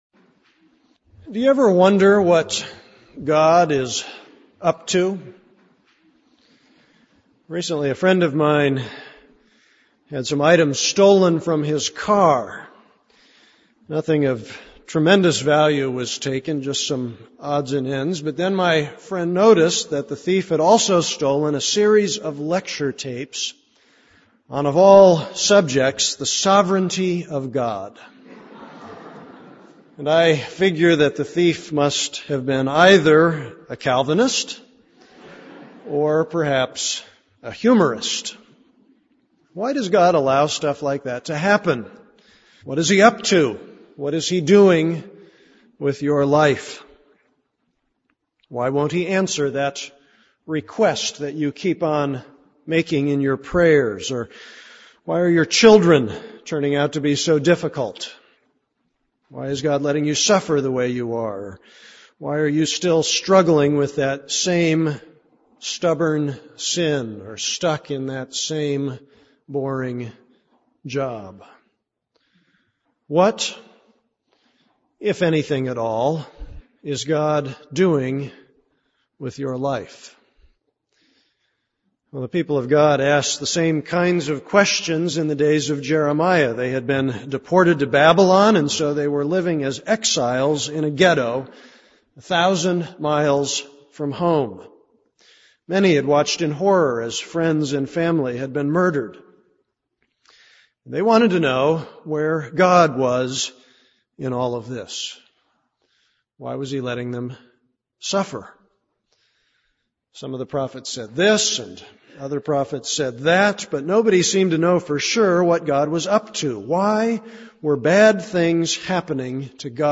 This is a sermon on Jeremiah 29:10-23.